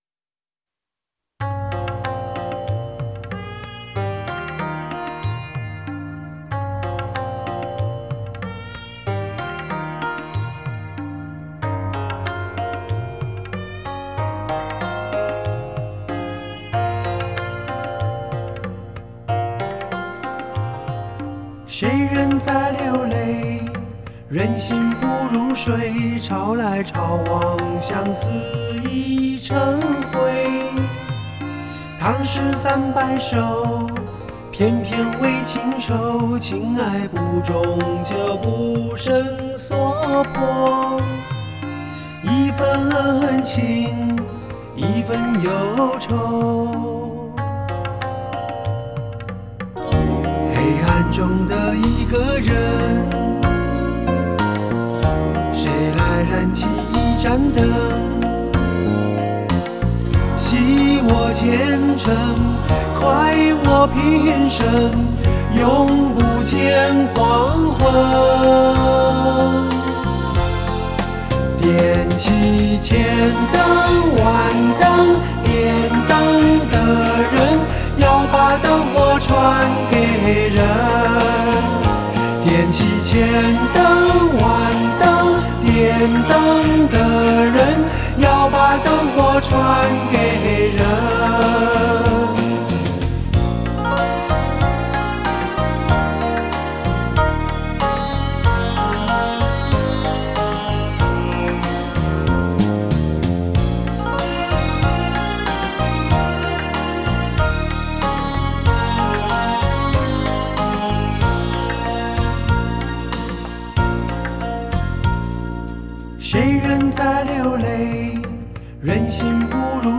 Ps：在线试听为压缩音质节选，体验无损音质请下载完整版 谁人在流泪，人心不如水，潮来潮往，相思已成灰。